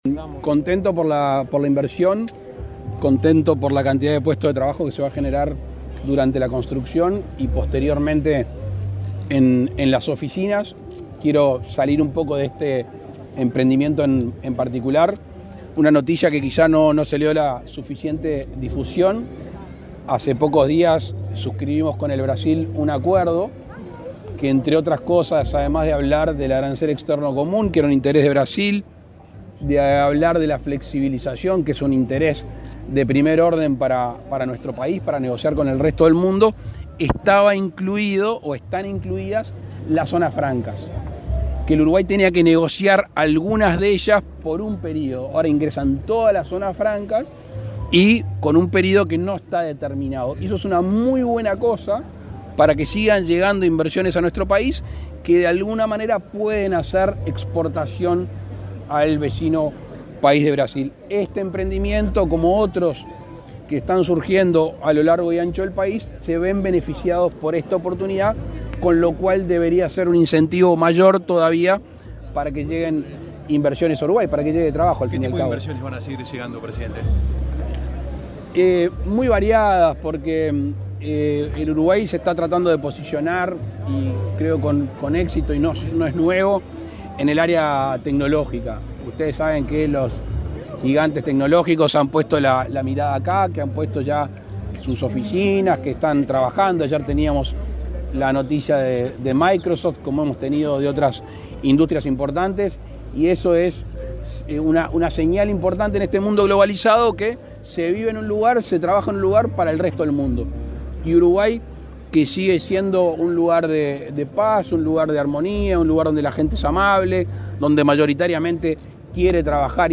Declaraciones del presidente Lacalle Pou a la prensa
Este viernes 24, el presidente de la República, Luis Lacalle Pou, participó en el acto de inicio de obras del World Trade Center de Punta del Este y,